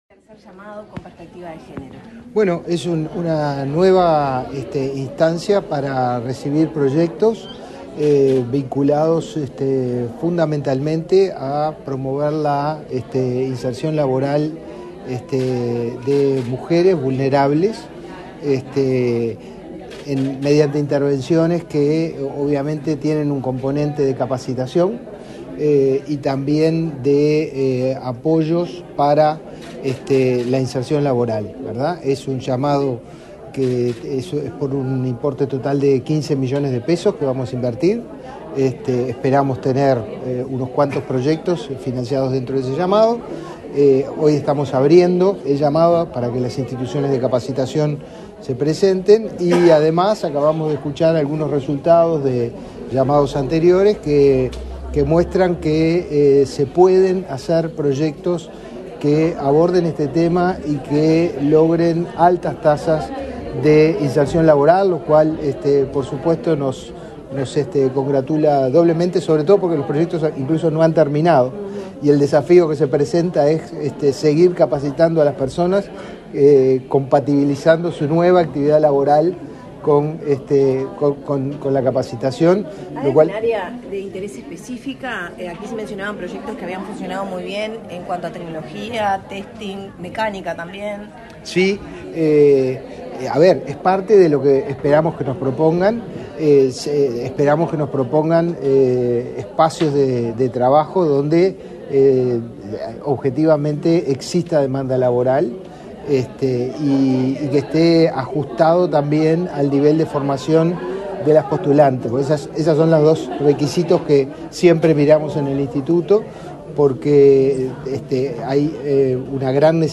Declaraciones a la prensa del director general de Inefop, Pablo Darscht
Declaraciones a la prensa del director general de Inefop, Pablo Darscht 06/06/2024 Compartir Facebook X Copiar enlace WhatsApp LinkedIn El Instituto Nacional de Empleo y Formación Profesional (Inefop) realizó, este 6 de junio, el acto de lanzamiento de la convocatoria 2024 para proyectos de formación profesional que potencien a mujeres para facilitar su inserción laboral. Tras el evento, el director del Inefop realizó declaraciones a la prensa.